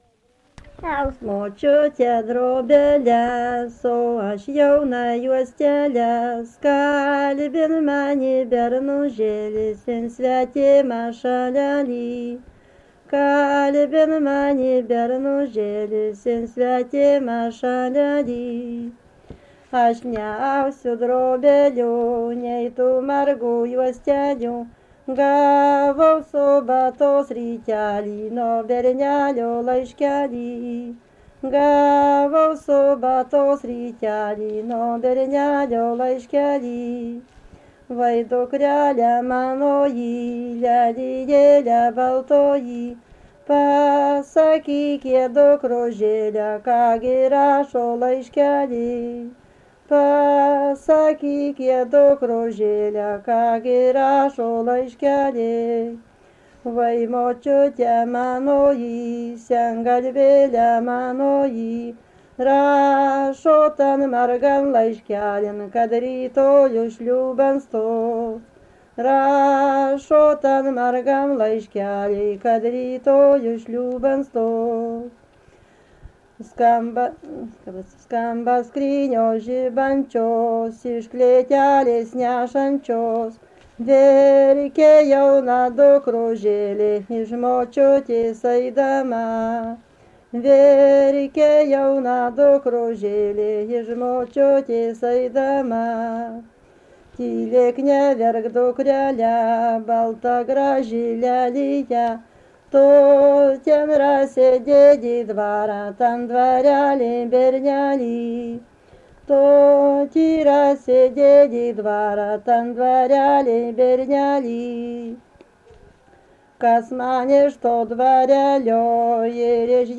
Subject daina
Erdvinė aprėptis Druskininkai
Atlikimo pubūdis vokalinis